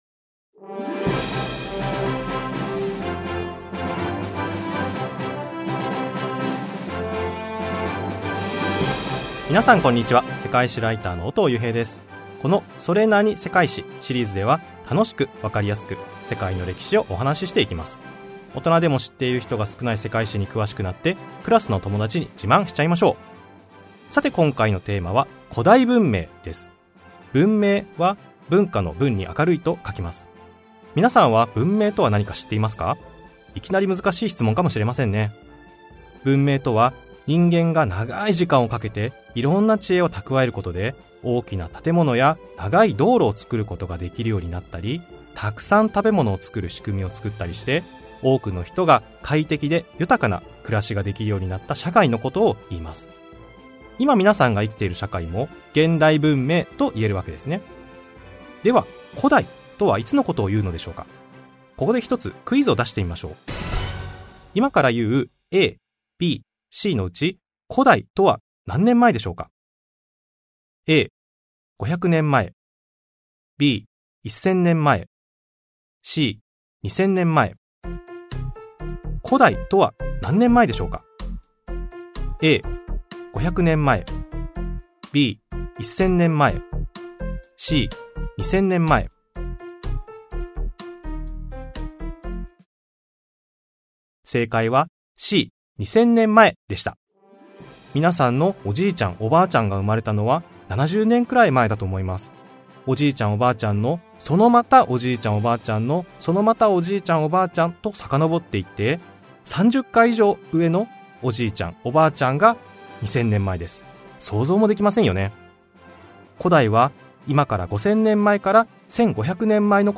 [オーディオブック] 小学生のうちに知っておきたい！それなに？世界史 Vol.1 古代文明